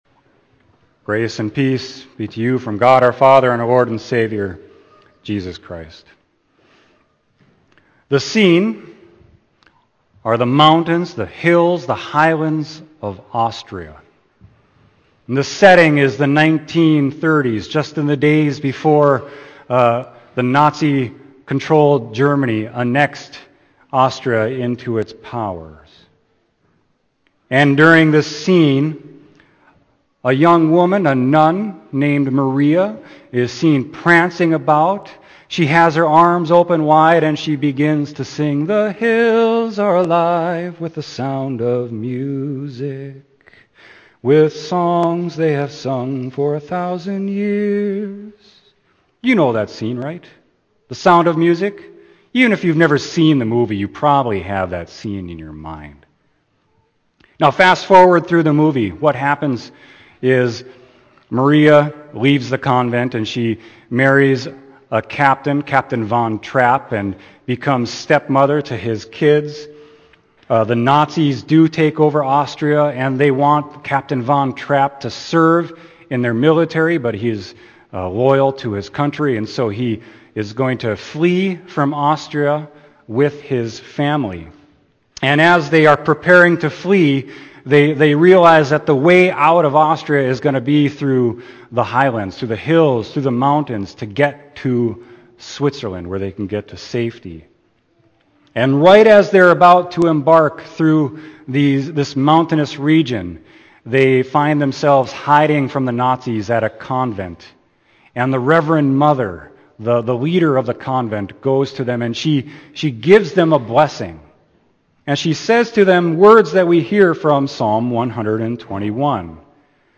Sermon: Psalm 121